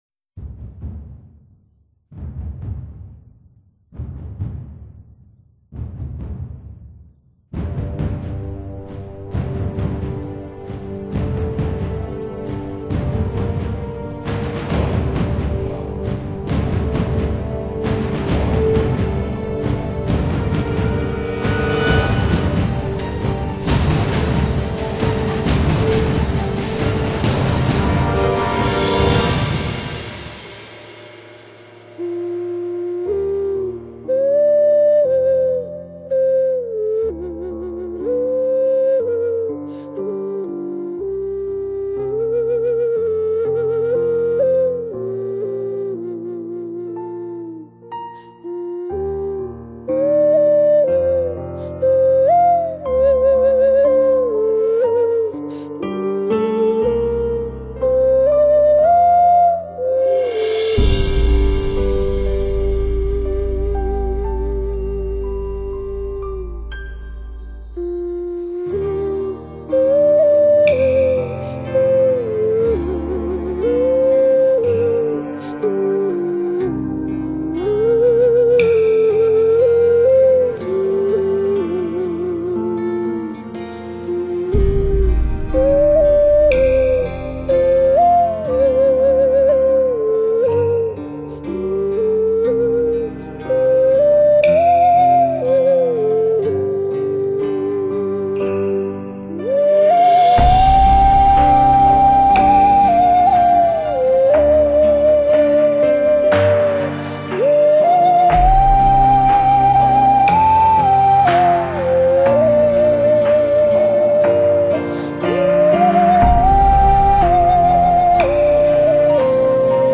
风雅陶笛系列发烧大碟
最古老的声音与现代的最新碰撞为您演绎新古典主义。